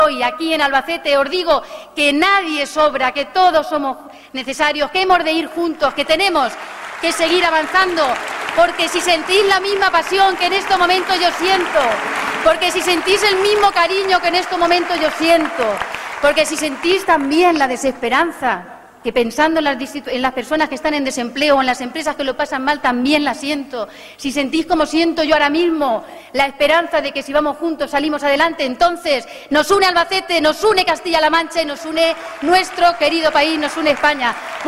En el acto han participado más de 2.000 personas, que llenaron dos salas del Palacio de Congresos y Exposiciones de Albacete, entre los que había militantes y simpatizantes del partido, pero también representantes de la sociedad, de los empresarios, los sindicatos, de los vecinos, de las asociaciones socio sanitarias, que recibieron un aplauso del público, por ser compañeros de viaje en el trabajo por Albacete y Castilla-La Mancha.